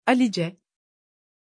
Pronunția numelui Alice
pronunciation-alice-tr.mp3